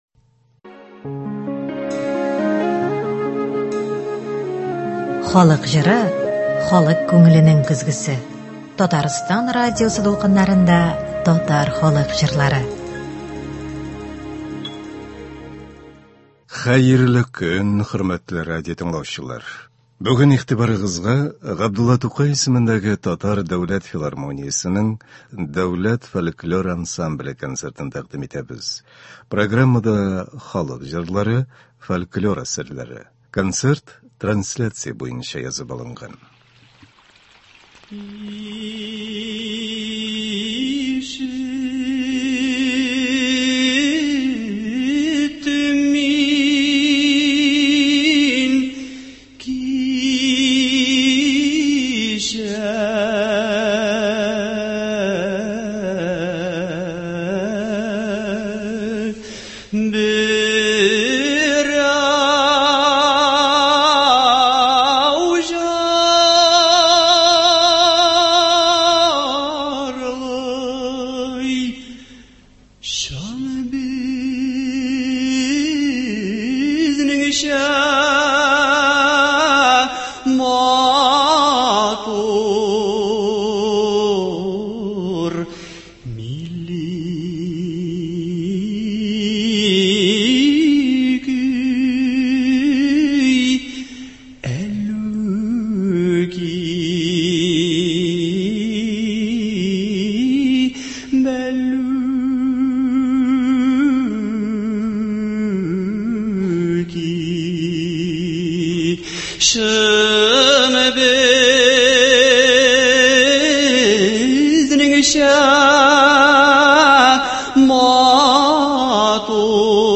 Татар халык җырлары (29.04.23)